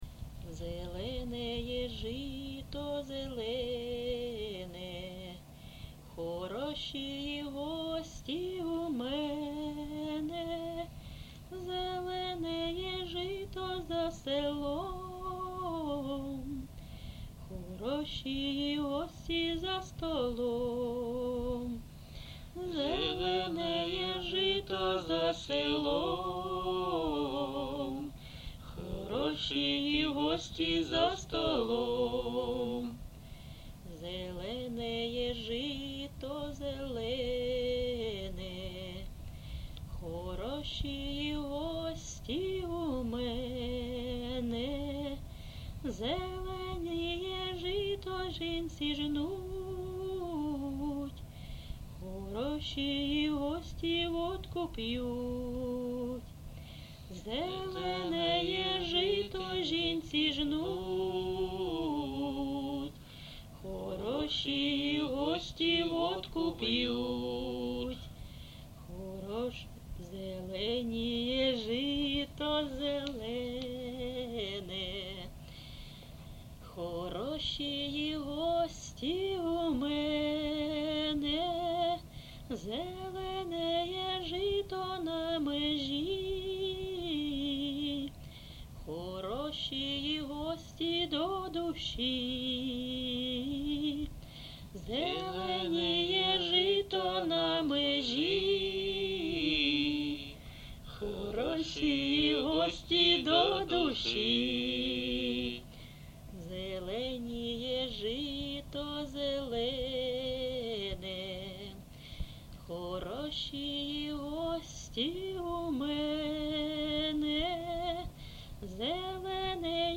ЖанрПісні літературного походження
Місце записум. Ровеньки, Ровеньківський район, Луганська обл., Україна, Слобожанщина